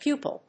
/pjúːp(ə)l(米国英語)/